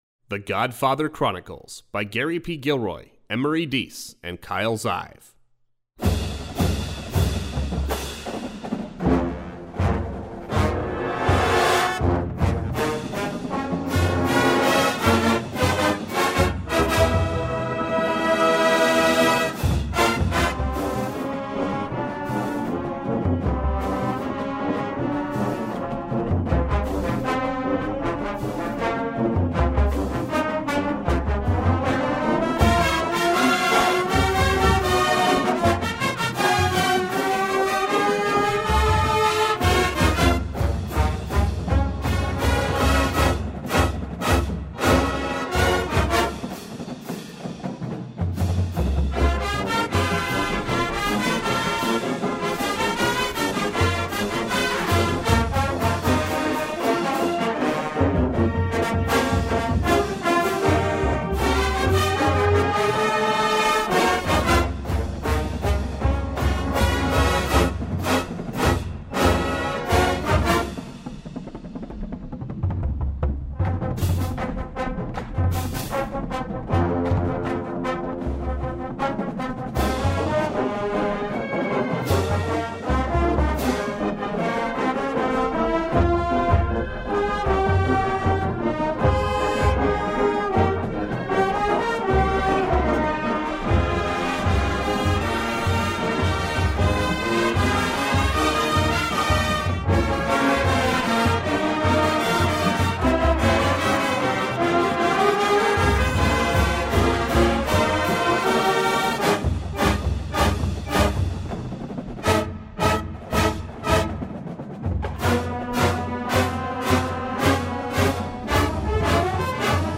contemporary Marching Band Show